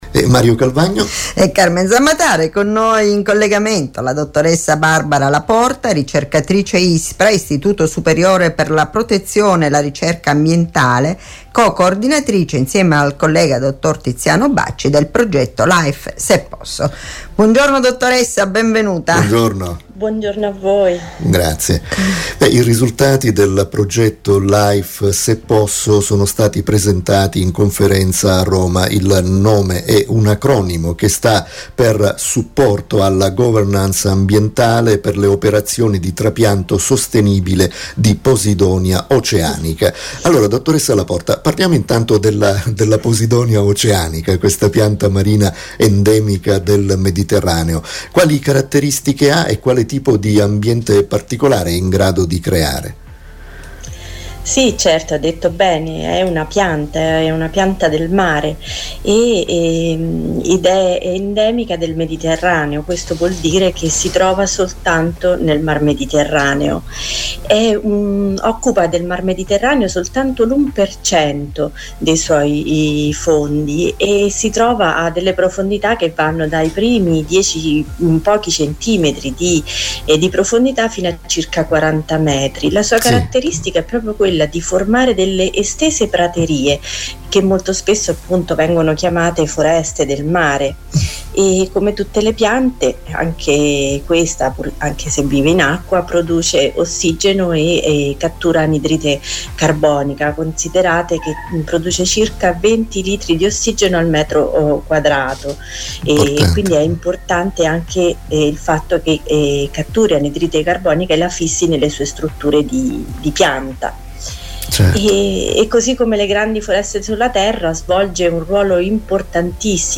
intervistano